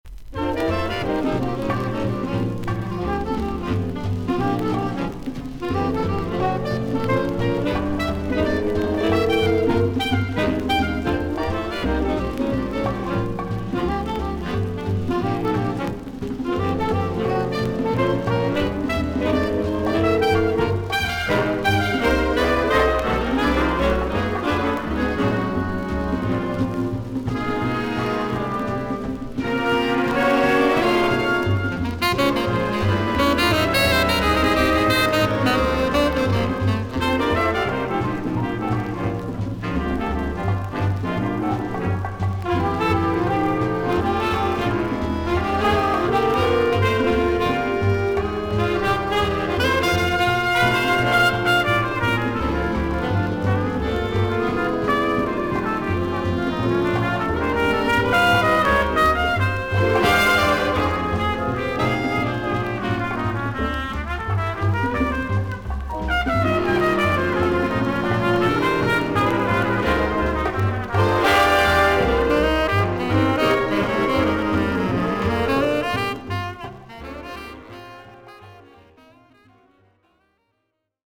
ジャズ・ピアニスト/アレンジャー/スウィート・ダンス・バンド。
VG+ 少々軽いパチノイズの箇所あり。少々サーフィス・ノイズあり。